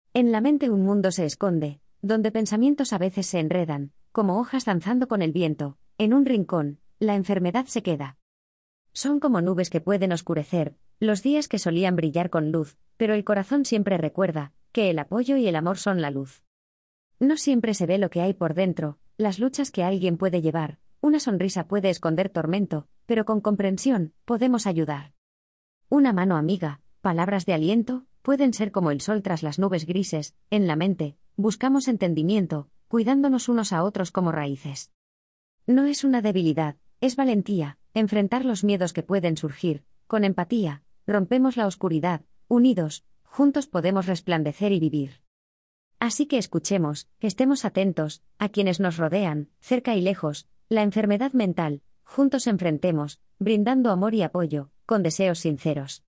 ud02eso_audio1_ap2_poema.mp3